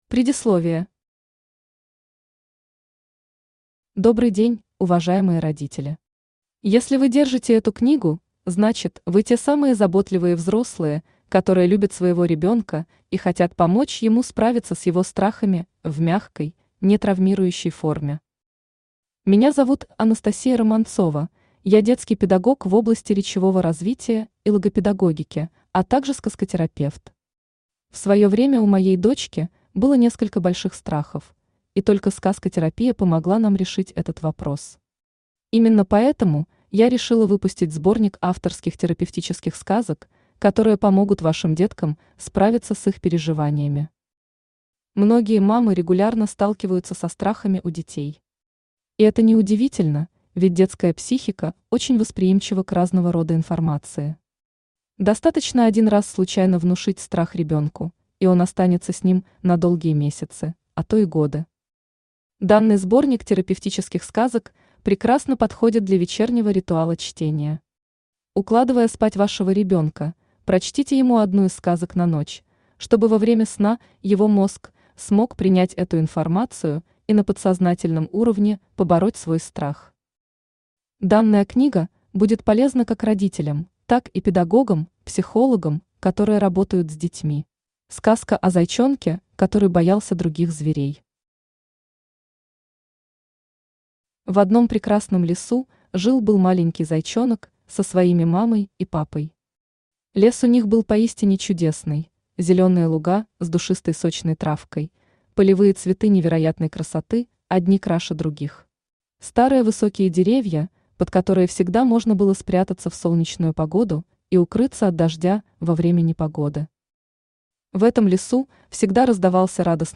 Аудиокнига Ребенок боится. Терапевтические сказки на ночь | Библиотека аудиокниг
Терапевтические сказки на ночь Автор Анастасия Романцова Читает аудиокнигу Авточтец ЛитРес.